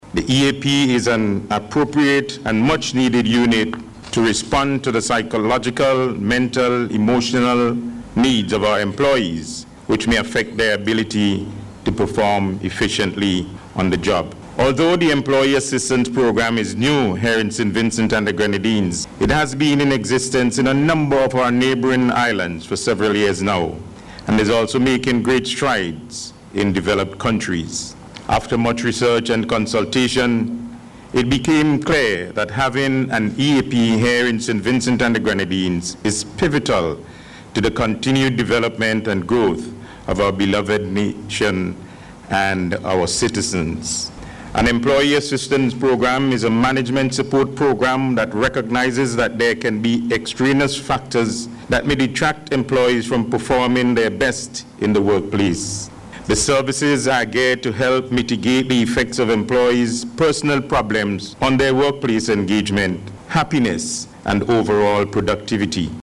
He made the point as he addressed the official launch of the Employee Assistance Programme Unit at the Methodist Church Hall this morning.